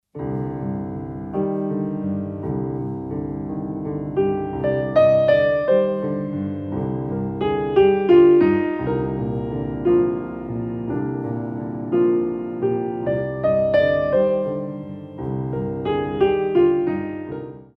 6/8 - 16x8